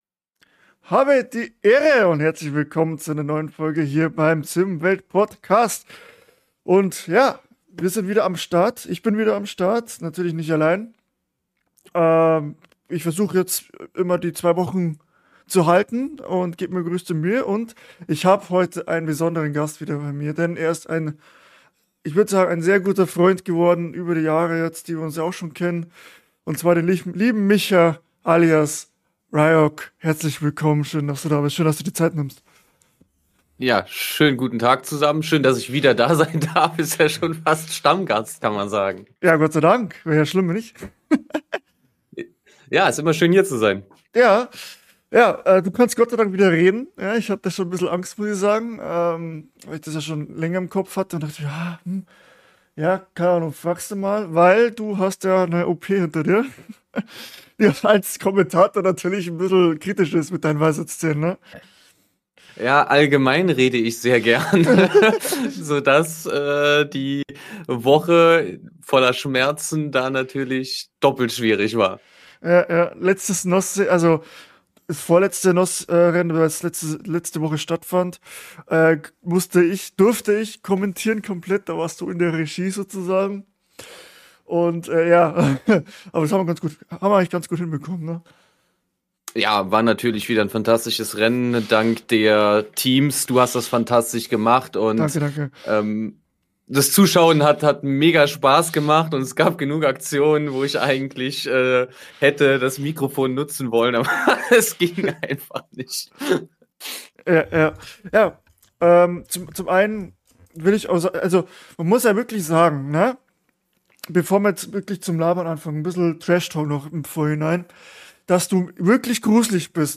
Wir haben uns über die aktuelle Simracingwelt unterhalten.